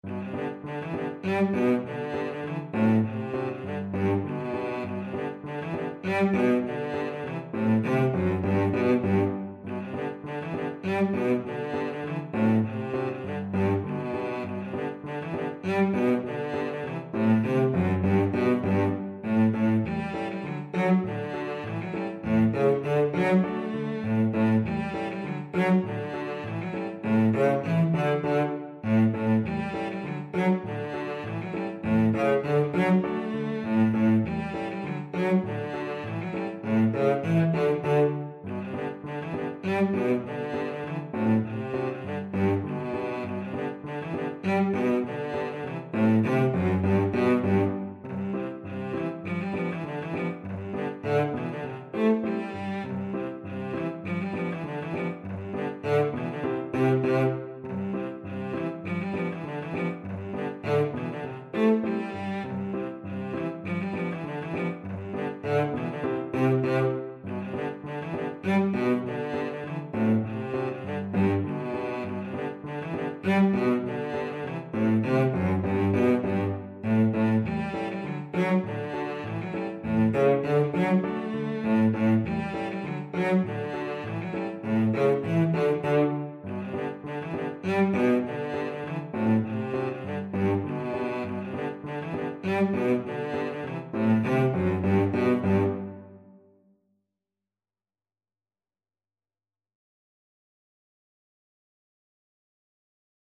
Cello
Traditional Music of unknown author.
2/4 (View more 2/4 Music)
F#3-A4
G major (Sounding Pitch) (View more G major Music for Cello )